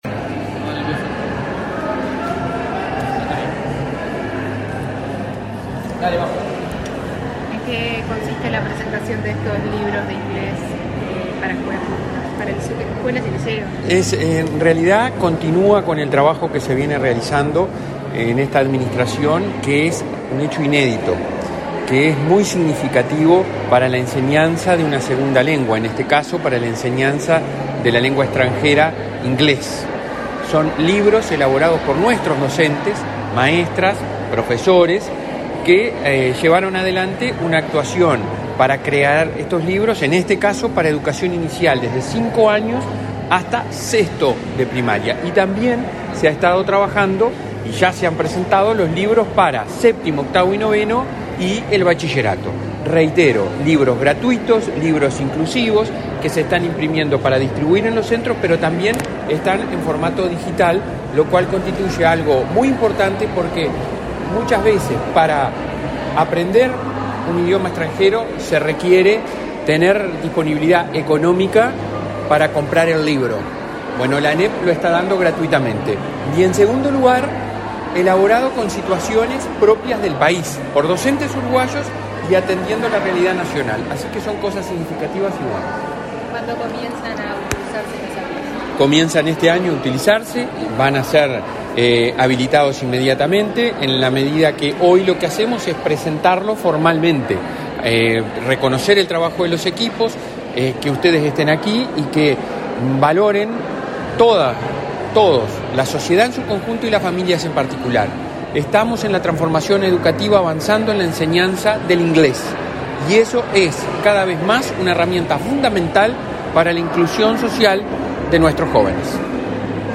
Declaraciones a la prensa del presidente de la ANEP, Robert Silva
Declaraciones a la prensa del presidente de la ANEP, Robert Silva 13/04/2023 Compartir Facebook X Copiar enlace WhatsApp LinkedIn Tras participar en la presentación de la serie de libros “Loving Uruguay”, desarrollados para aulas de educación primaria uruguayas con enseñanza de inglés presencial, este 13 de abril, el presidente de la Administración Nacional de Educación Pública, Robert Silva, realizó declaraciones a la prensa.